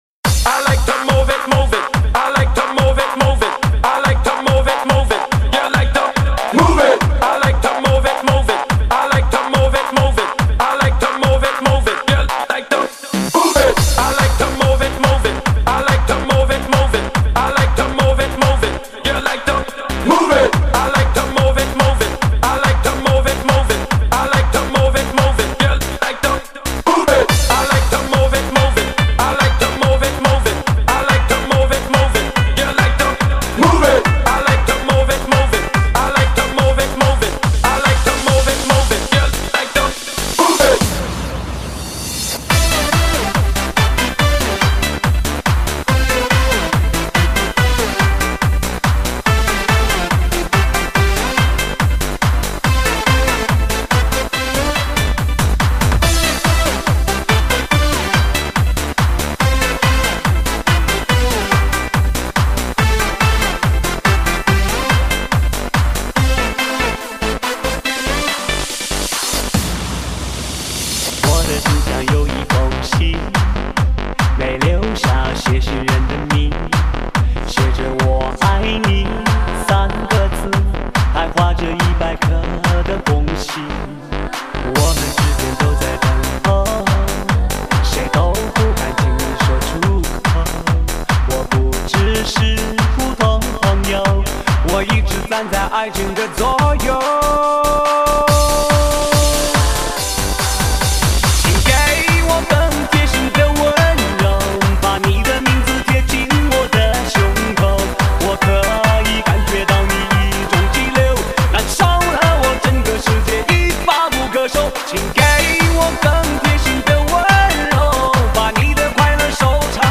唱片类型：电音炫音
体味动感节奏，赶走精神疲惫！